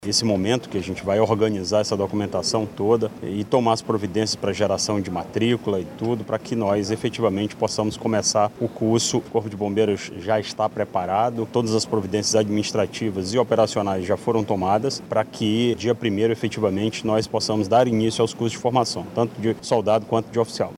O comandante-geral do Corpo de Bombeiros do Amazonas, coronel Orleilso Muniz, explica que esta é a primeira etapa de integração dos novos membros da corporação.